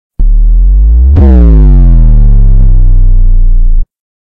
Board Bass Drop Sound Effect Sound Effects Free Download